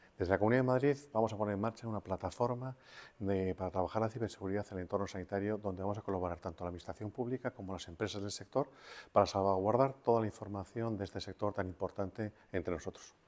Declaraciones del consejero ]